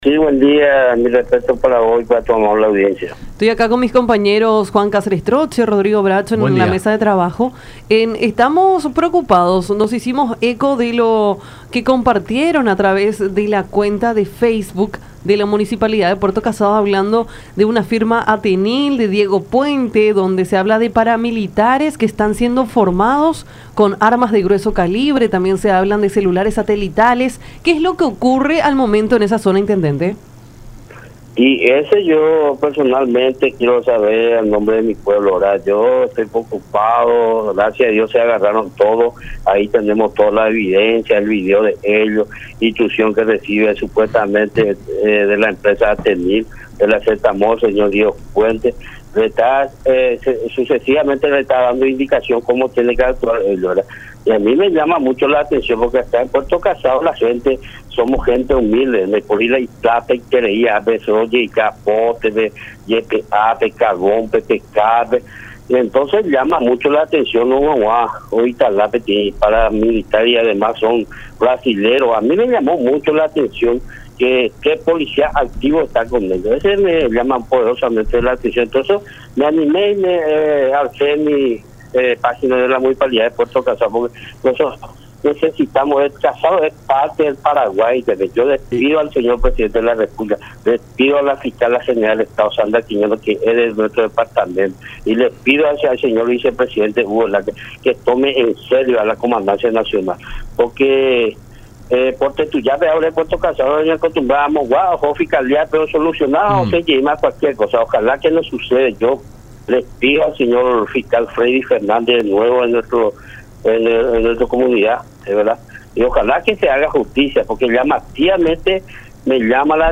“A mí me llama mucho la atención. Estoy bastante preocupado por lo que está pasando en Puerto Casado, porque somos gente humilde y nos sorprende que se instalen acá paramilitares, encima son brasileños y con armamento pesado. Seguramente tienen antecedentes. Le pido al Presidente de la República, a la Fiscal General del Estado y al Vicepresidente que nos den una mano ante esta situación”, expresó el intendente de Puerto Casado, Hilario Adorno, en diálogo con Nuestra Mañana por La Unión, manifestando que, a raíz de este escenario de incertidumbre, determinó la publicación de esta situación en las redes sociales oficiales de la municipalidad.